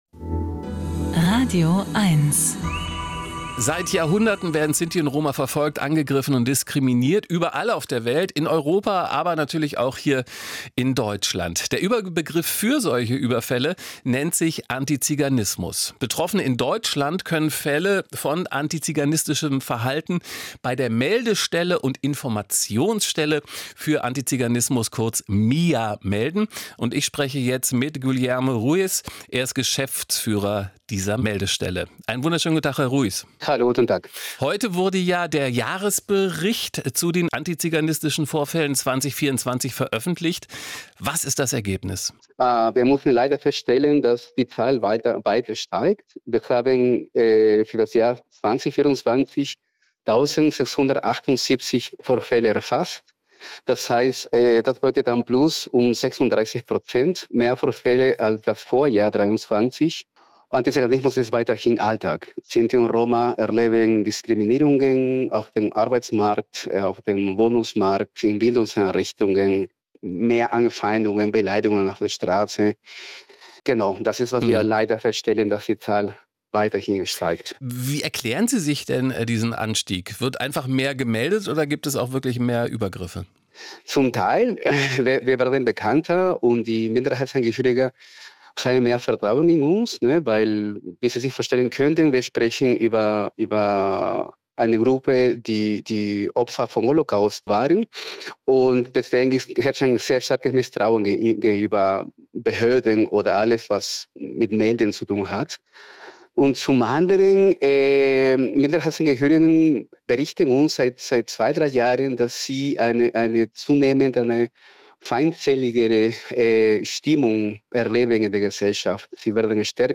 Mehr Hass und Hetze: Antiziganismus in Deutschland nimmt zu - Ein Interview mit Radioeins - Melde- und Informationsstelle Antiziganismus
MIA Bund im Gespräch mit Radioeins anlässlich der Veröffentlichung des 3. Jahresberichts zu antiziganistischen Vorfällen in Deutschland.